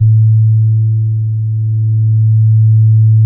XP SUBASE E2.wav